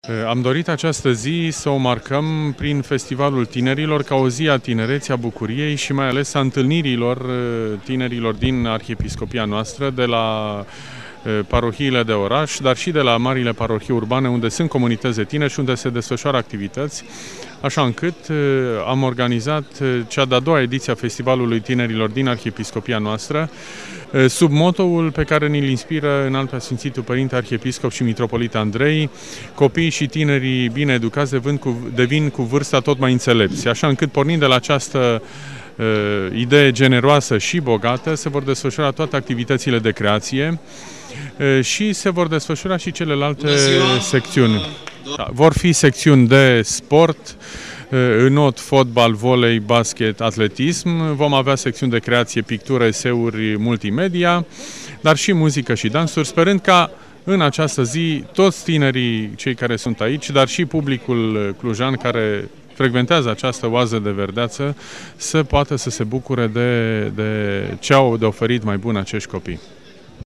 Manifestarea, care  avut loc în parcul sportiv „Iuliu Hațieganu” din Cluj-Napoca, a debutat la ora 10:00 și a reunit tineri din toate cele nouă protopopiate.